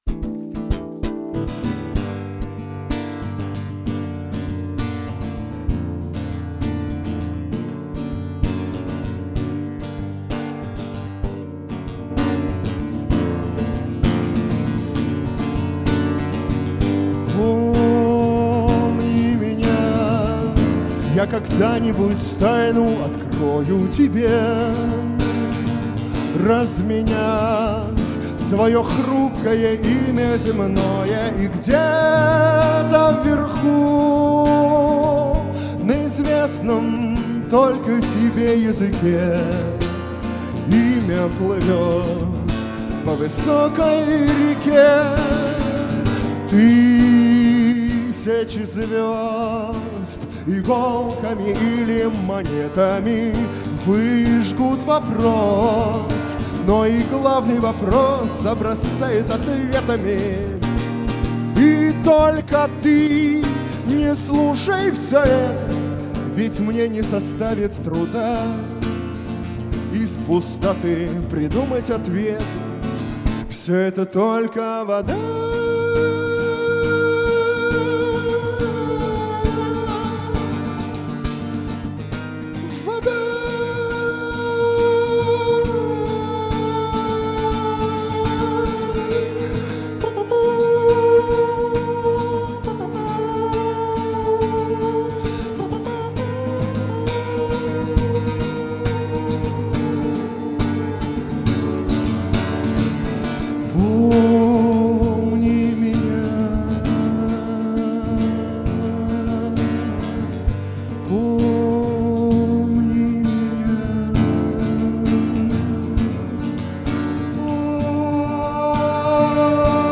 авторская 30-ти минутка
20 "Исторический" слет восточного побережья США